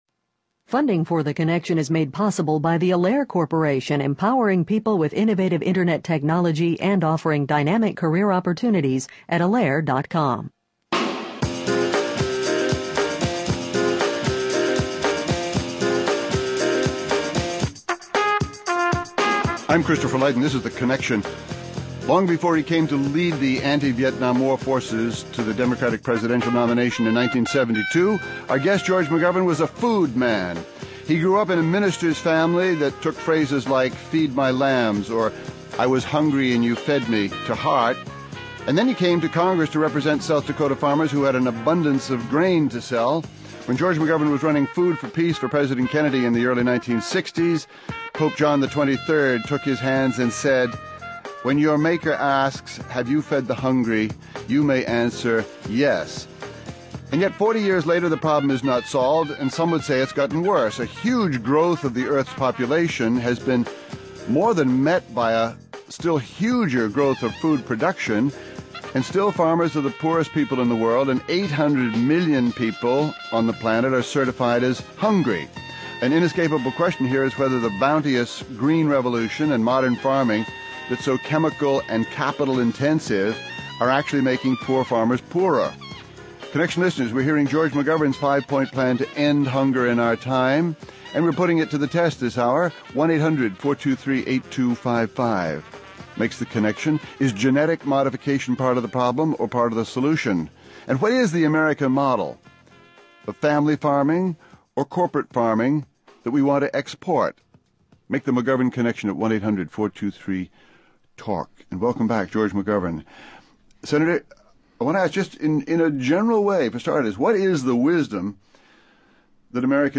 (Hosted by Christopher Lydon)